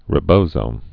(rĭ-bōsō, -zō)